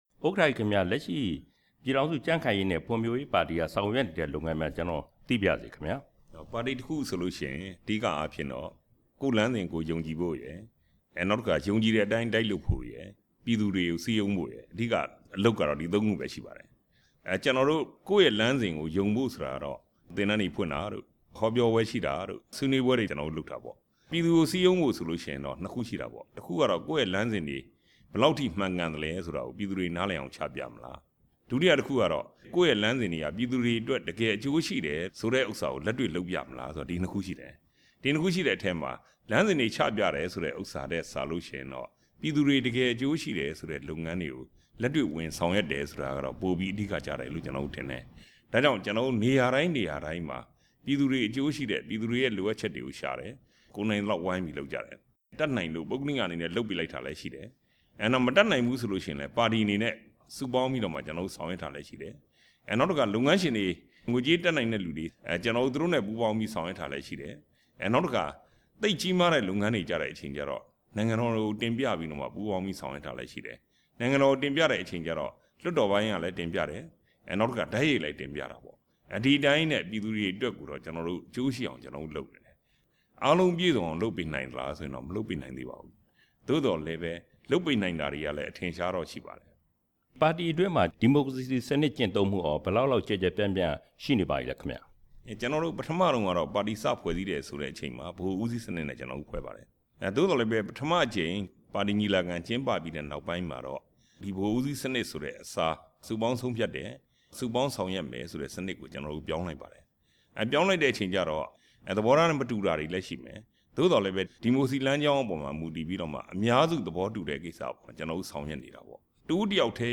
ဦးဌေးဦးနဲ့ မေးမြန်းချက်